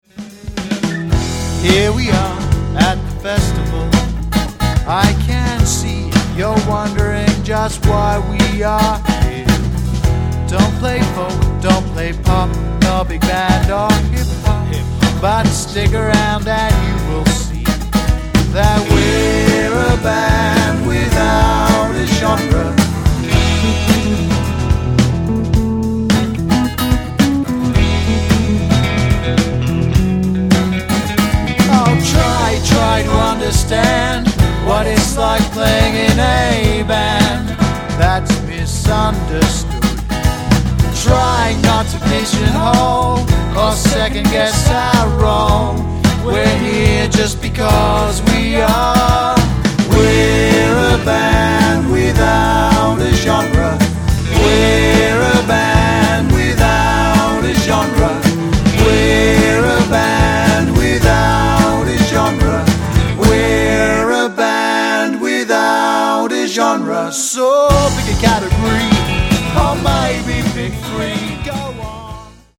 Recorded at Newmarket Studios